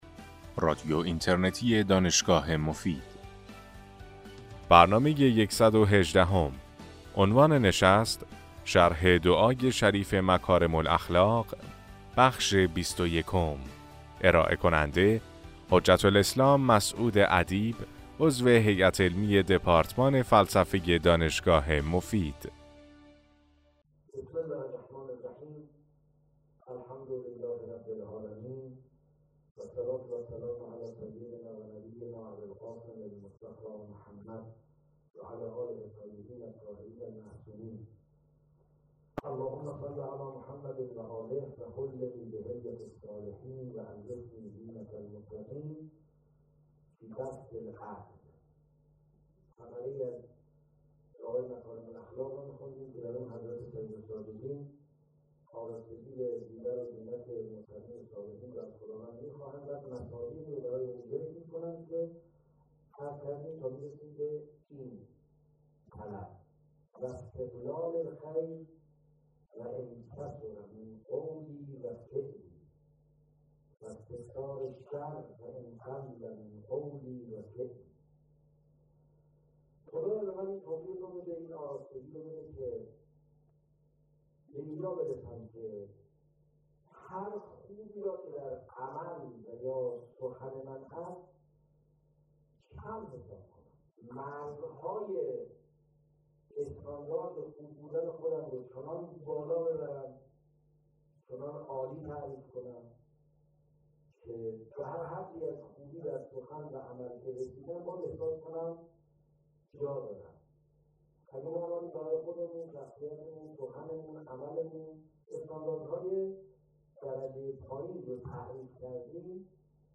در این سلسله سخنرانی که در ماه رمضان سال 1395 ایراد شده است به شرح و تفسیر معانی بلند دعای مکارم الاخلاق (دعای بیستم صحیفه سجادیه) می پردازند.